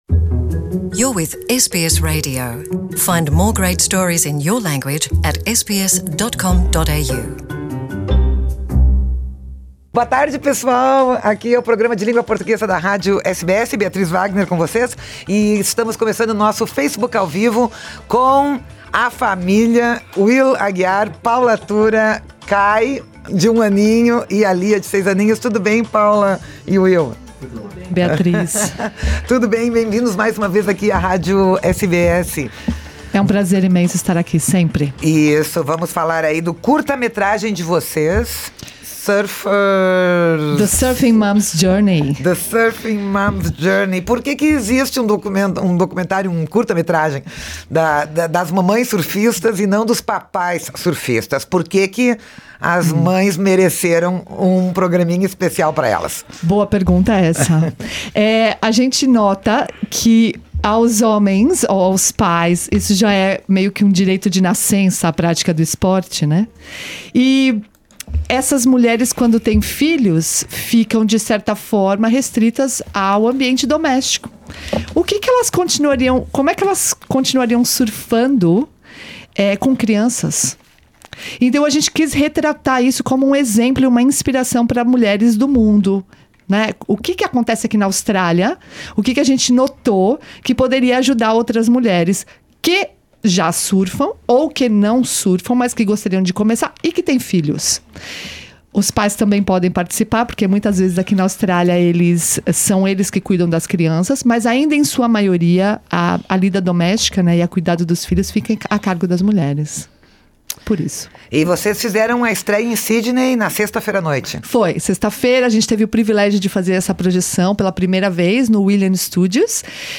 estiveram nos estúdios da SBS, em Sydney